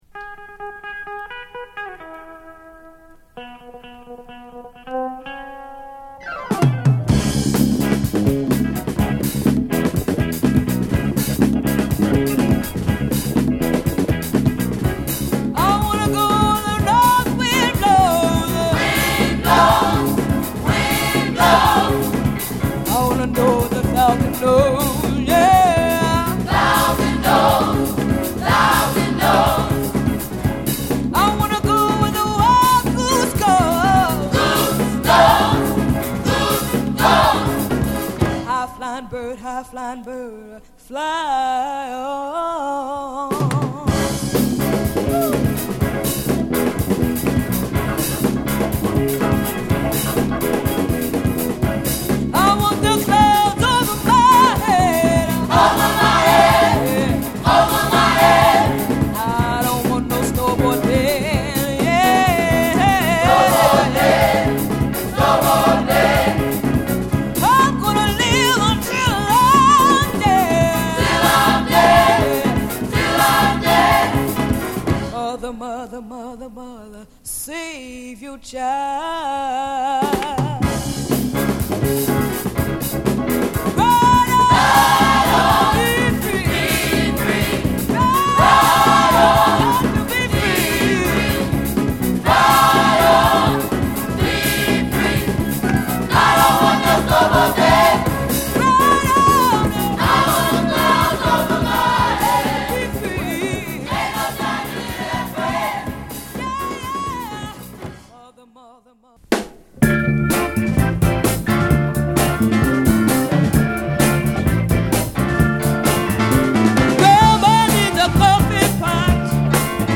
疾走感溢れるベースにドラム、勢いマンテンの演奏にパワフルなヴォーカルと重厚なコーラス